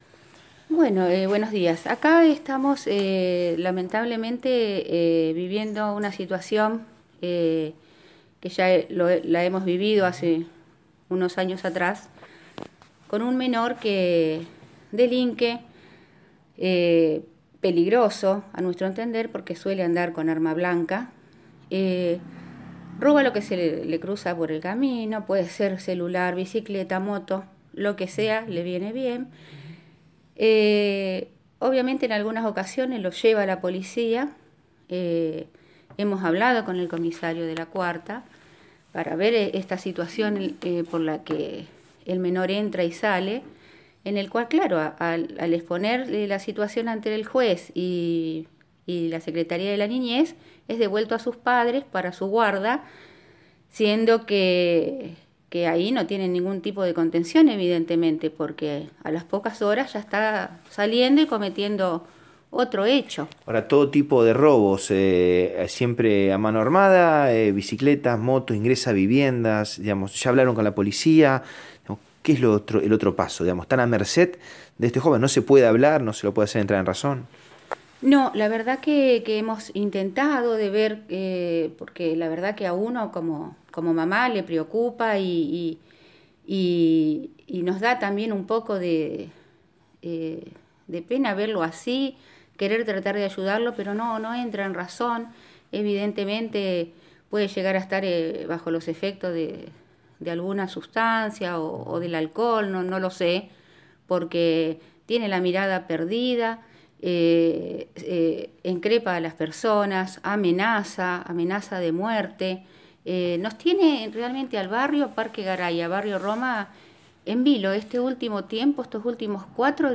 En diálogo con Radio EME, una vecina de uno de los barrios anteriormente mencionados sostuvo que «vivimos una situación que ya la atrevesamos años atrás con un menor al que nosotros consideramos peligroso porque roba con arma blanca lo que se le cruza por el camino, desde motos y bicicletas, hasta realiza entraderas a las casas de los barrios«.